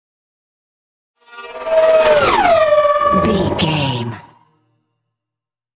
Sci fi vehicle whoosh large
Sound Effects
futuristic
whoosh